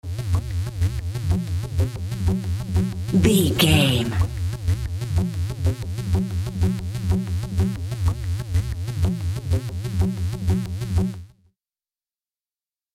Groovy Modern Electronic Stinger.
Ionian/Major
E♭
dreamy
ethereal
happy
hopeful
synthesiser
drum machine
house
techno
trance
instrumentals
synth leads
synth bass
upbeat